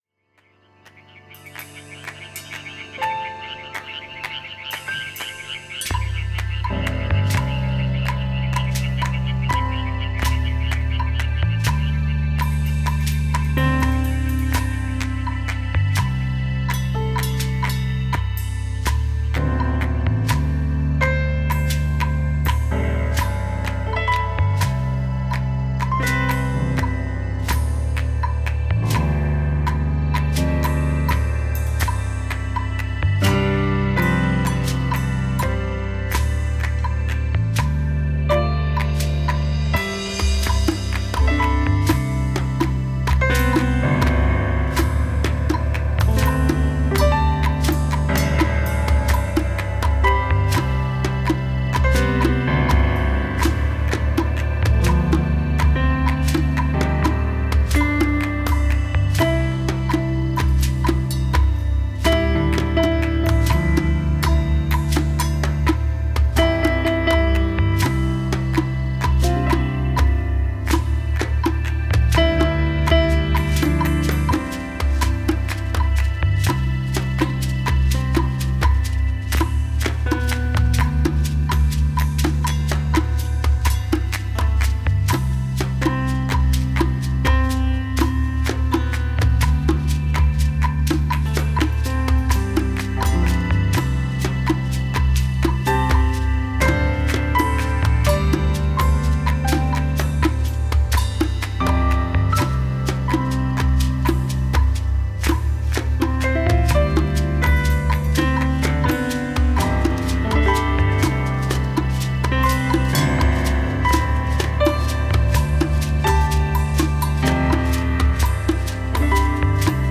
Piano
Percussion